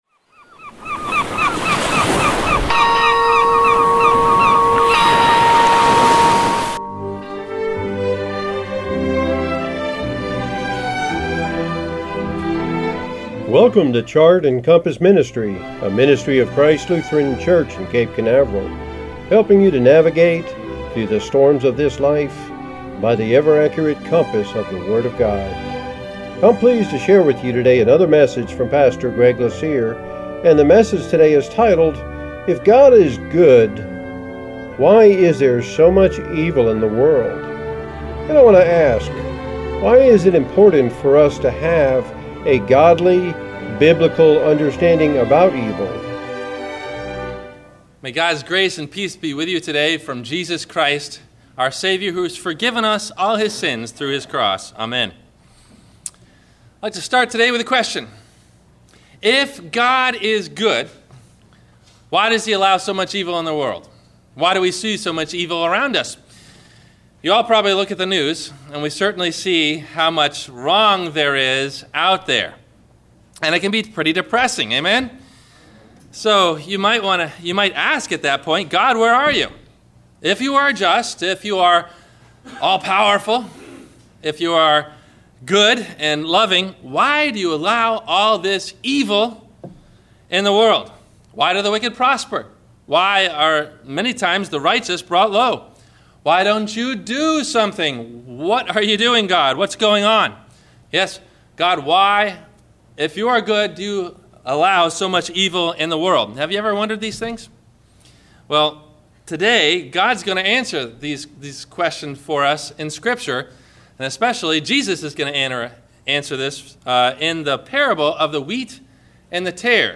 If God is Good, Why is there so much Evil in the World? – WMIE Radio Sermon – March 13 2017 - Christ Lutheran Cape Canaveral
Questions asked before the Sermon message: